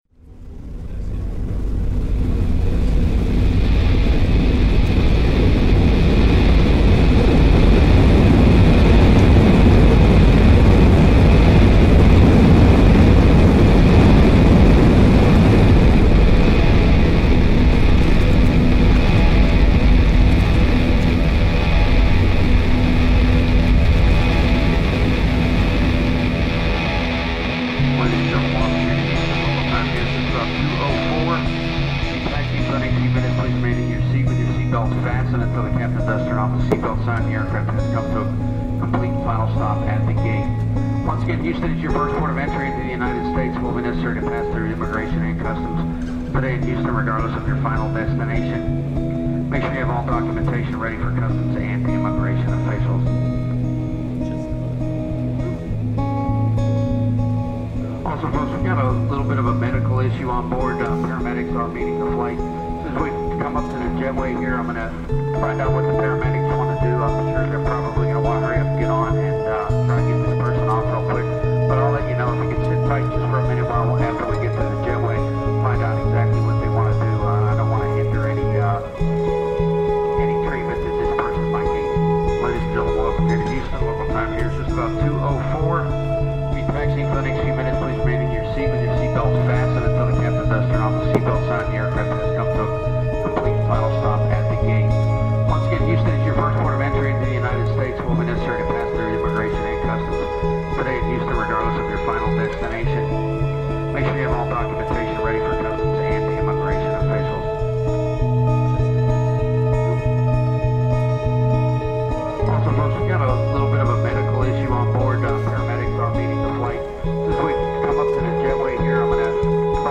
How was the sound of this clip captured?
Houston Airport aircraft landing reimagined